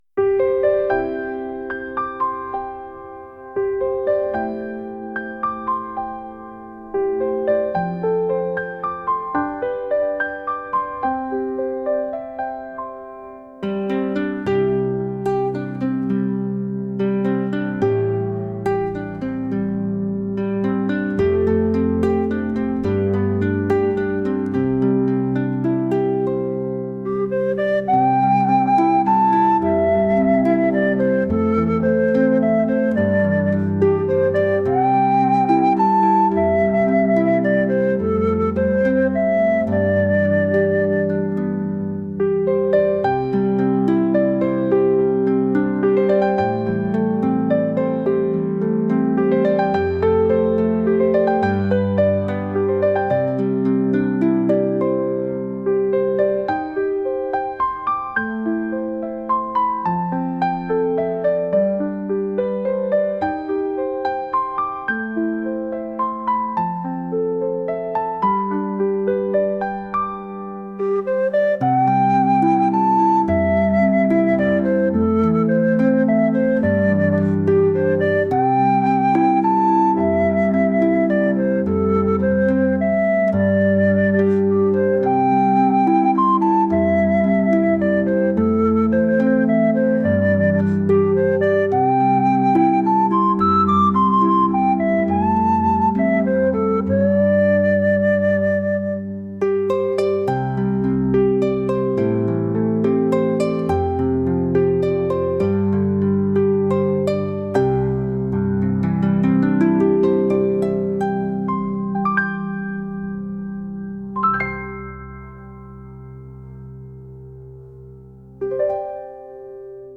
夜に暖炉にあたりながら本をゆったり読むような雰囲気の音楽です。